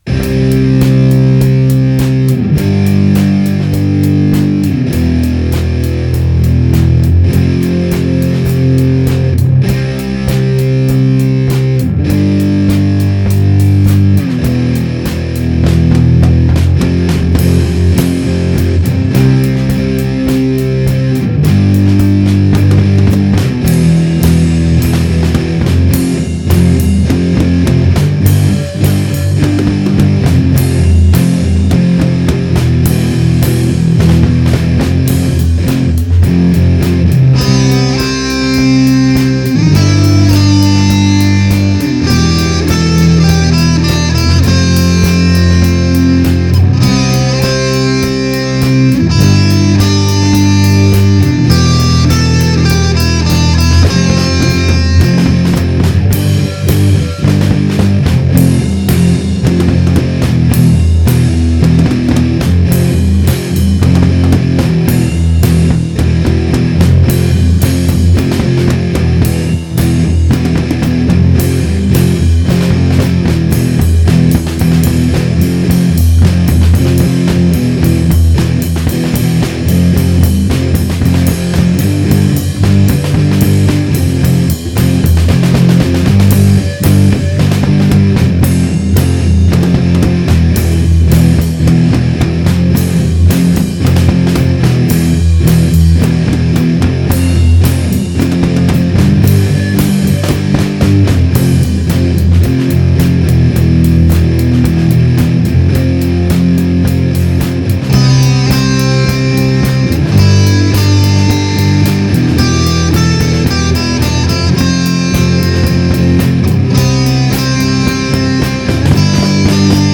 a driving instrumental that gains force as it rolls (demo
Guitars, drums and bass